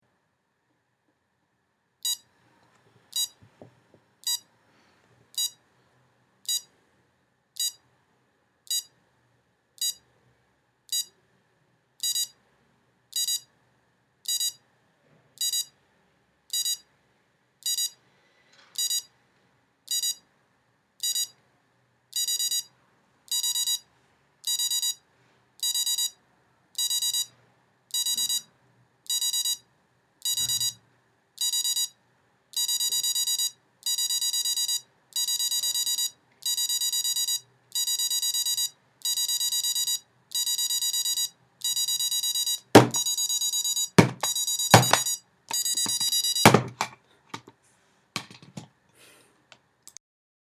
wecker
wecker.mp3